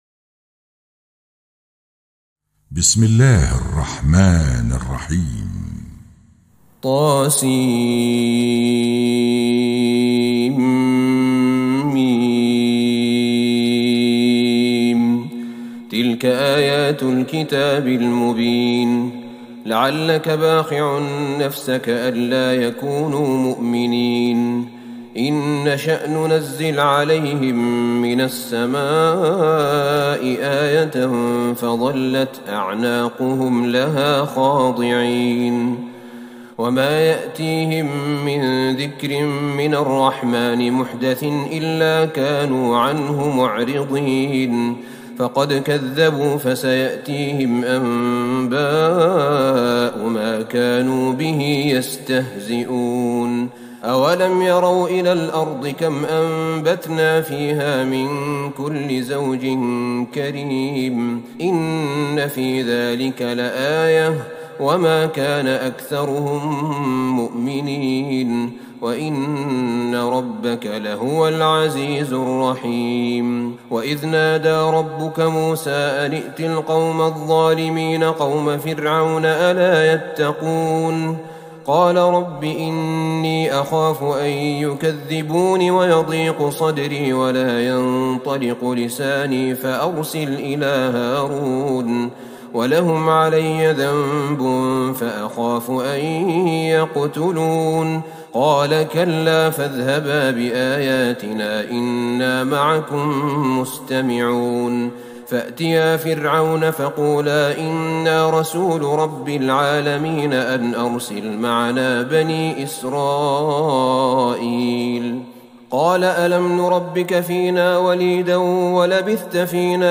تراويح ٢٣ رمضان ١٤٤١هـ من سورة الشعراء { ١-١٩١ } > تراويح الحرم النبوي عام 1441 🕌 > التراويح - تلاوات الحرمين